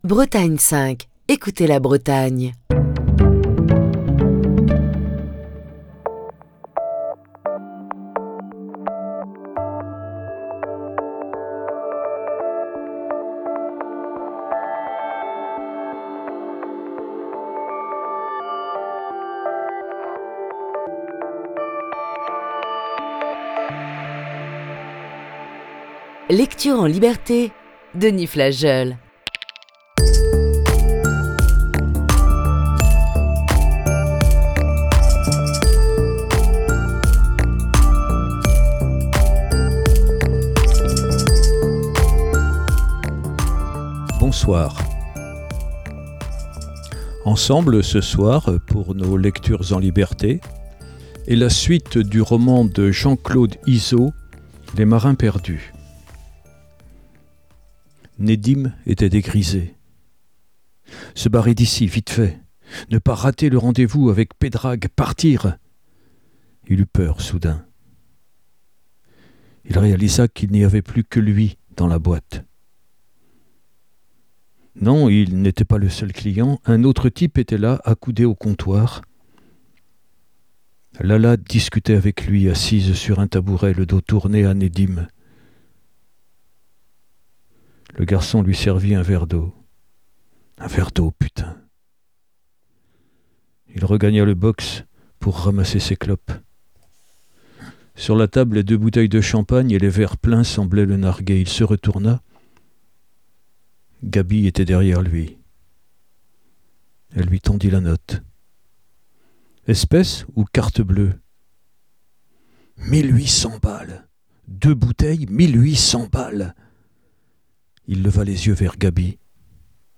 la lecture du livre de Jean-Claude Izzo, "Les marins perdus"